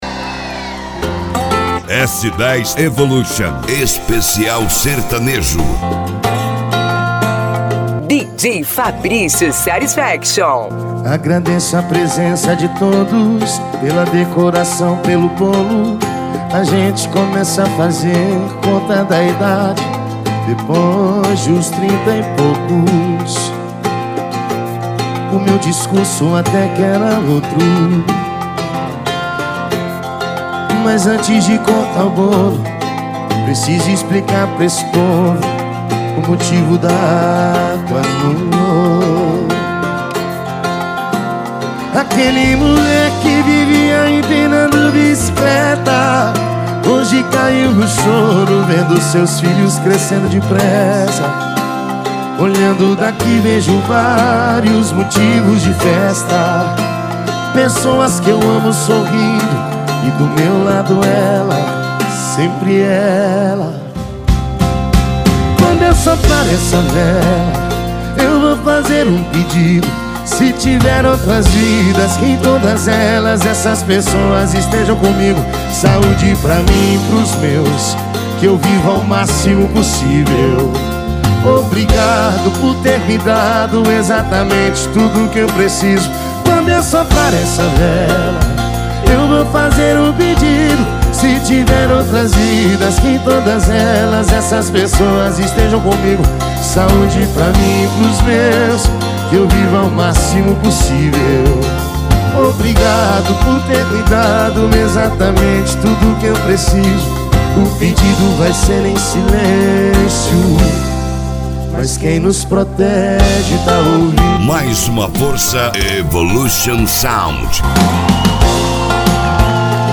SERTANEJO